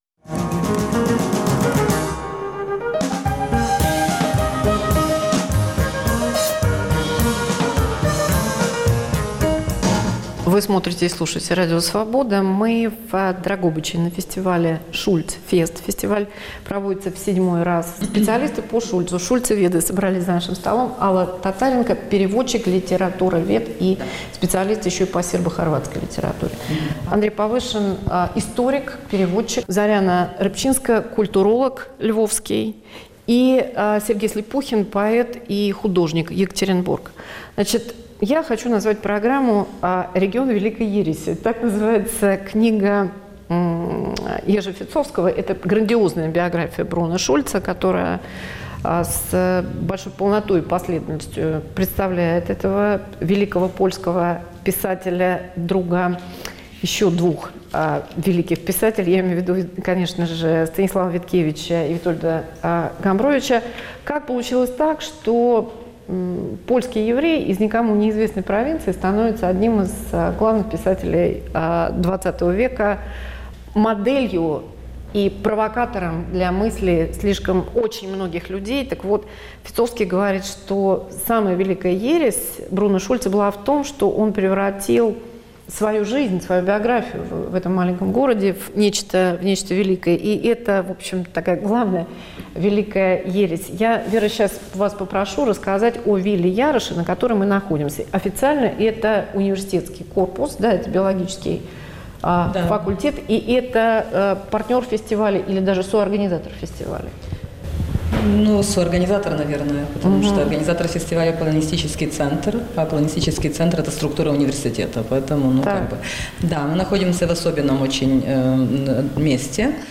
На Шульц-фесте в Дрогобыче, Западная Украина. Писатель и художник Бруно Шульц как герой современной культуры.